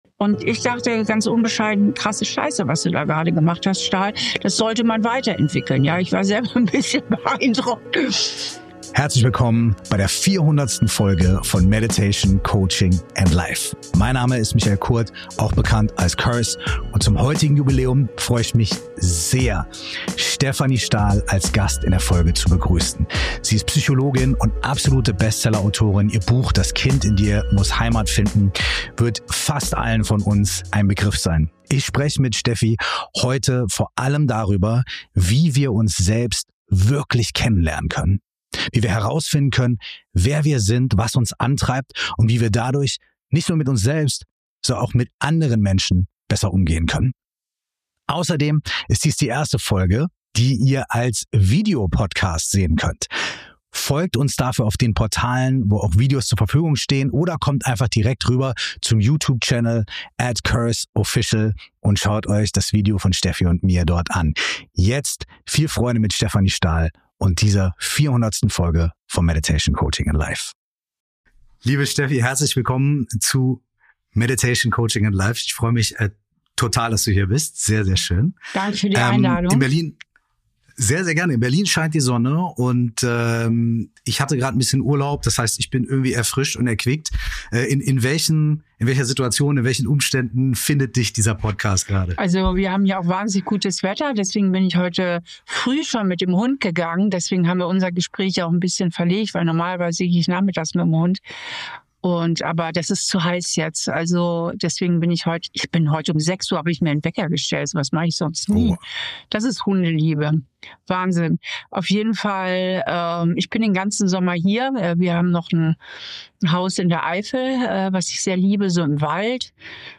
In der 400. Jubiläumsfolge spricht Curse mit Bestsellerautorin und Psychologin Stefanie Stahl über die Kraft der Persönlichkeitstypen, darüber was unsere Antreiber sind, Selbstreflektion und wie daraus gewonnene Erkenntnisse nicht nur unser eigenes Leben, sondern auch unsere Beziehungen transformieren können.